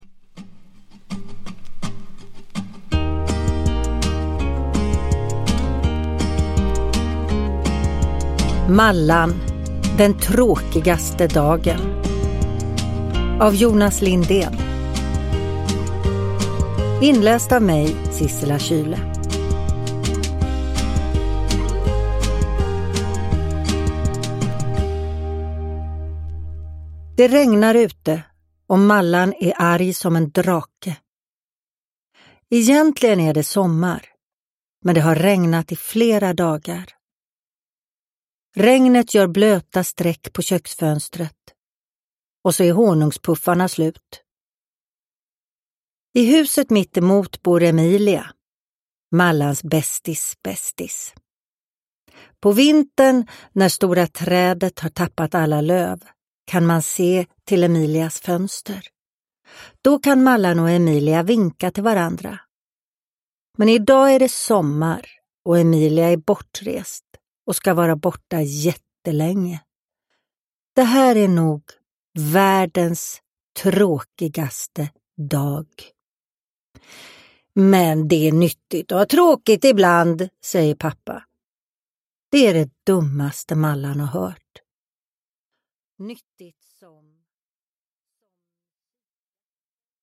Uppläsare: Sissela Kyle